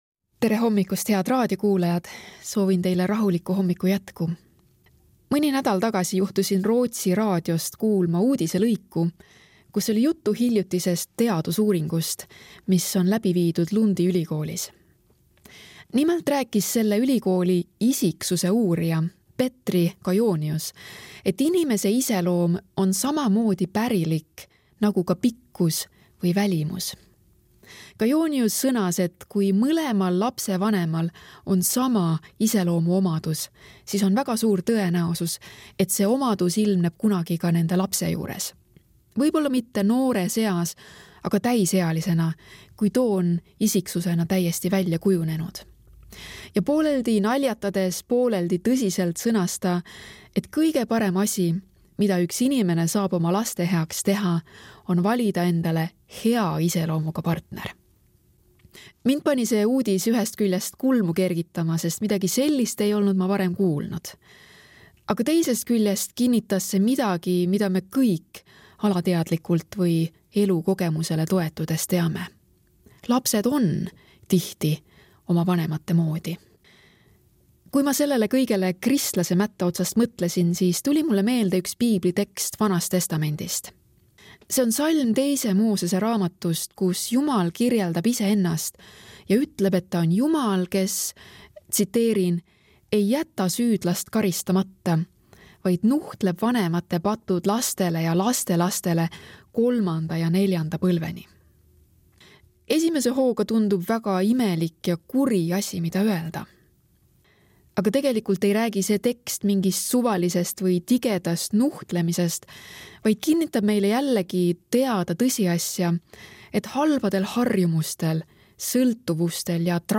hommikumõtisklus ERR-s 11.11.2025